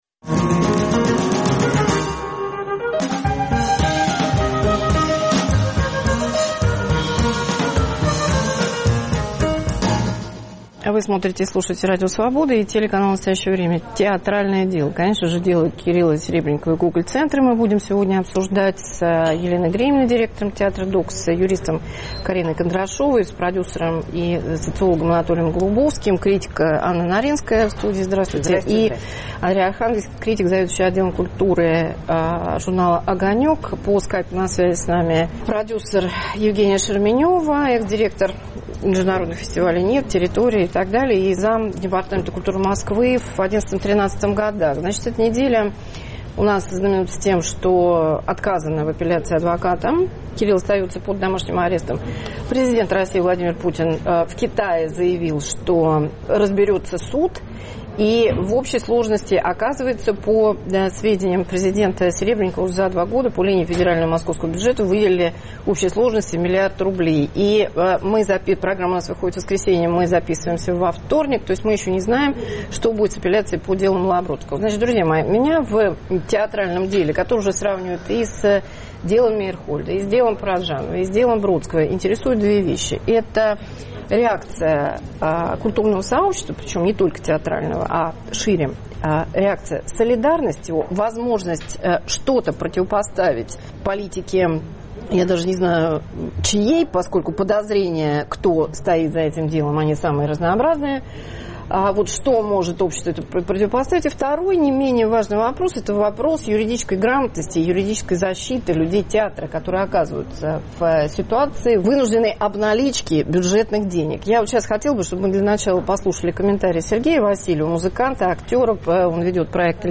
Дело Гоголь-центра и его уроки для общества: необходимость солидарности, юридической грамотности и реформ в системе театрального финансирования. Обсуждают критики, продюсеры, юристы.